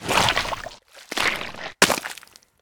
crafting_slime_01.ogg